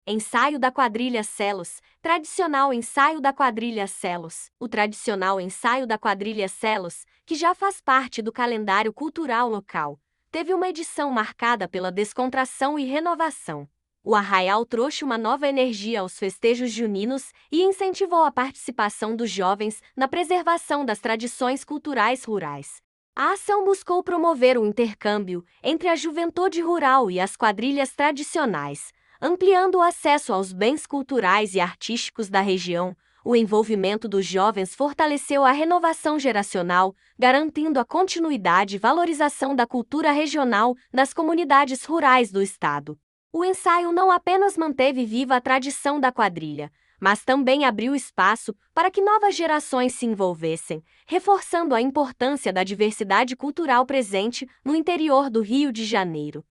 Tradicional ensaio da Quadrilha Celos